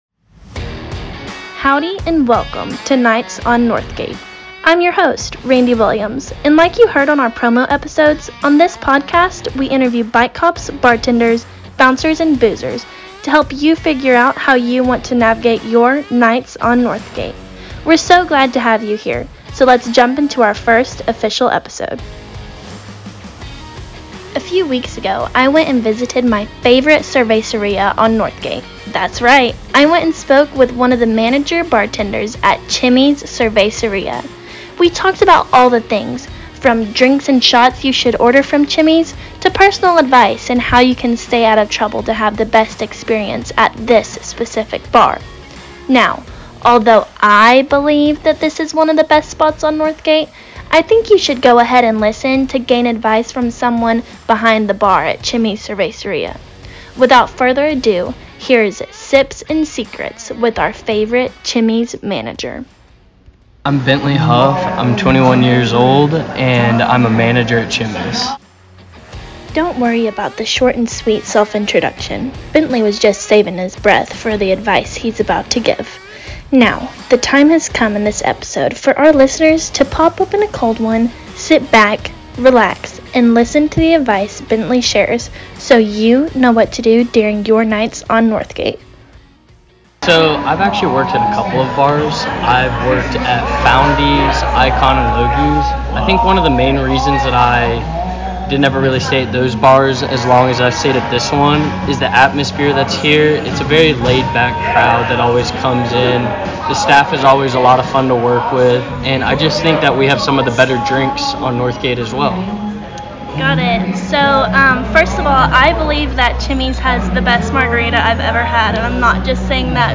In episode 1, we will talk to a few managers, bartenders, and other employees working at College Stations’ favorite bars.